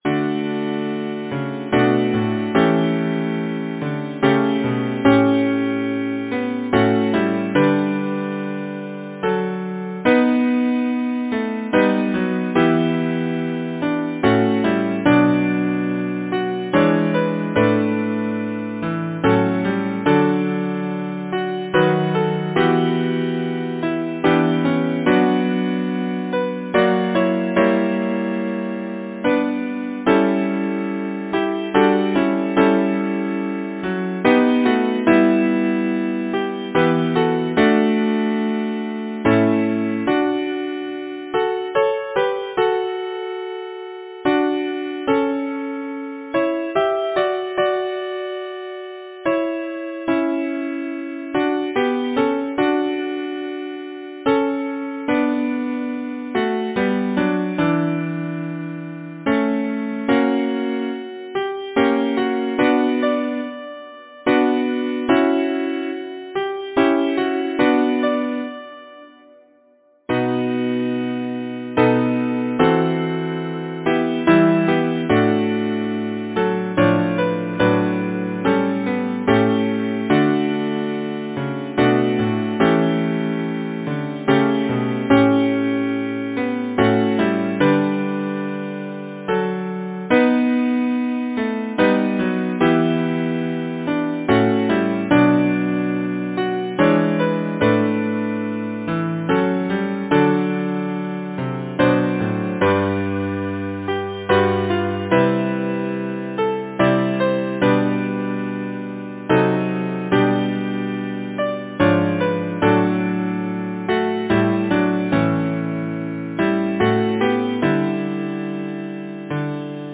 Title: Soldier, rest! Composer: Arthur George Colborn Lyricist: Walter Scott Number of voices: 4vv Voicing: SATB Genre: Secular, Partsong
Language: English Instruments: A cappella